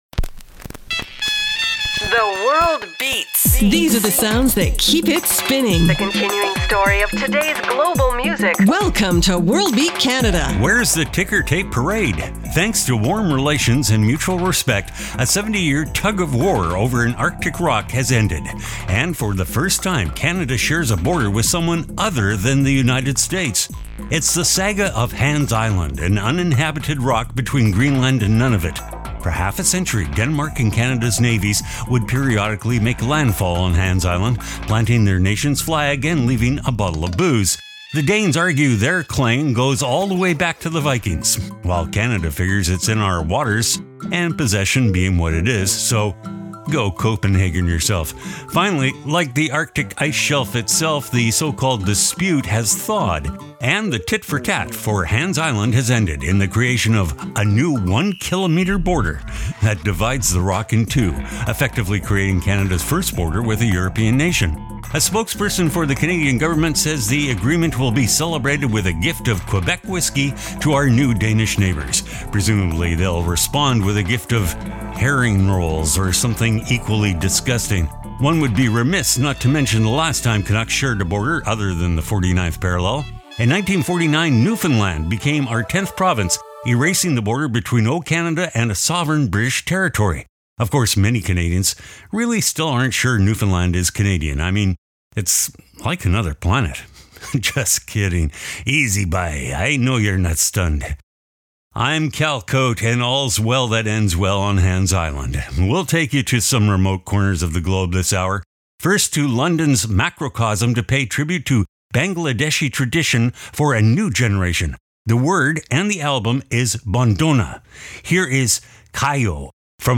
exciting global music alternative to jukebox radio